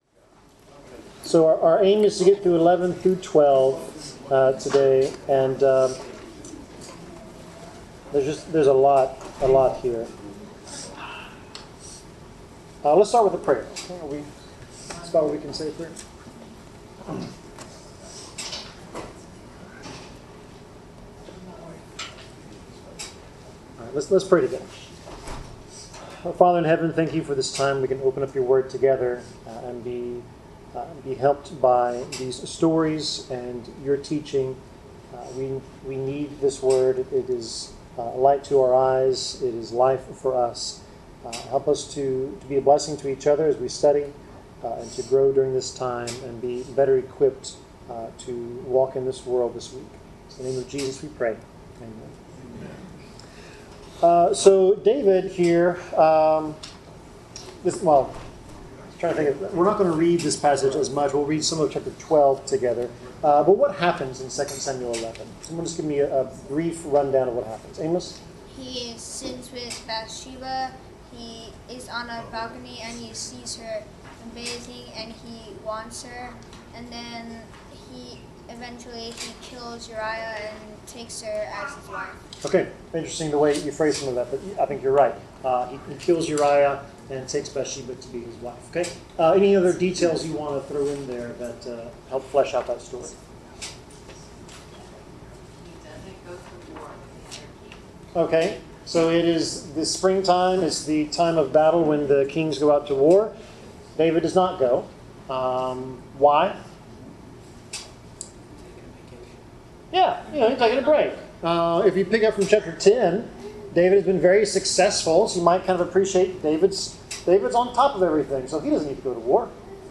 Bible class: 2 Samuel 11-12 (When David Despised the LORD)
Service Type: Bible Class